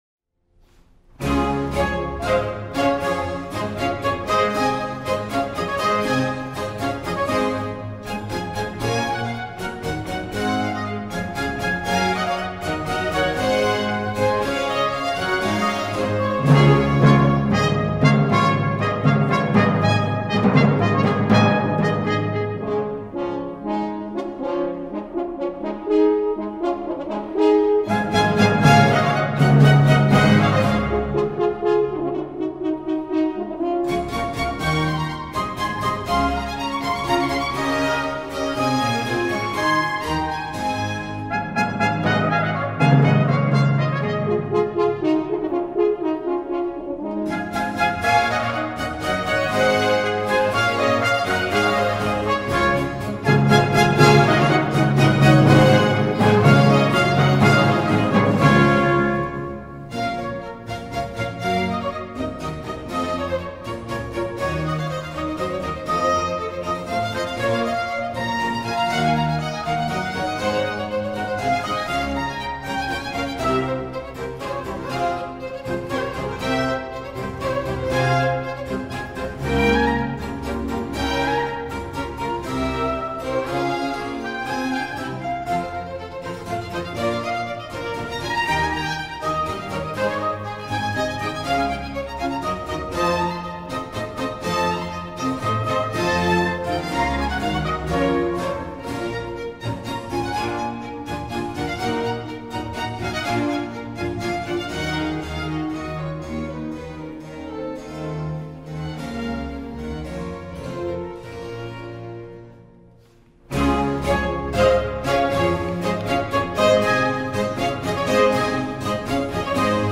یکی از نخستین آثار جالب توجه او که برای اجرا در فضای باز و روی رود تیمز ساخته شده قطعه‌ای به نام «آب» است که همۀ ویژگی‌های اصلی دورۀ باروک ازجمله یکنواختی تندا و تکرار تم اصلی را می‌توان به‌وضوح در آن شنید: